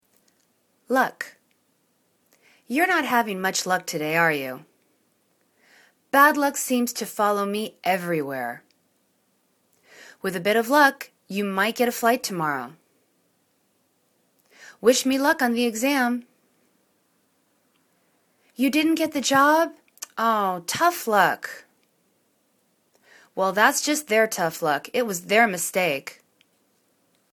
luck    /luk/    [U]